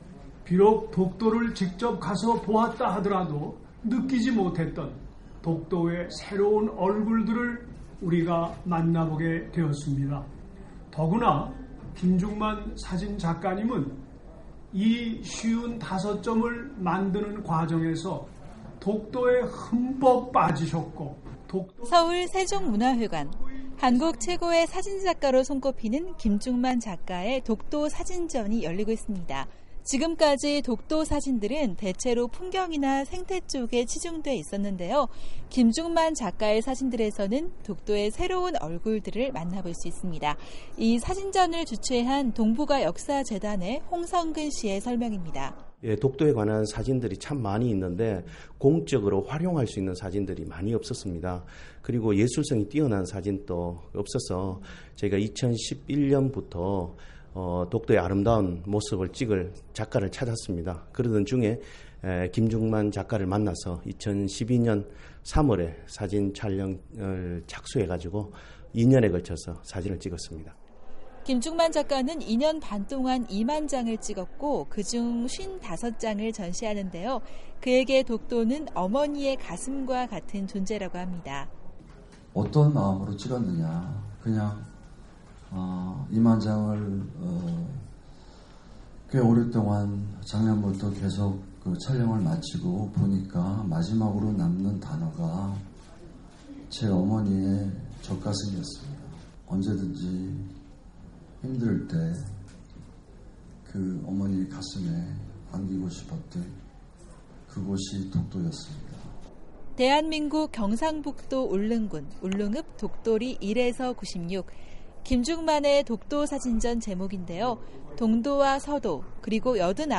기자가 전해드립니다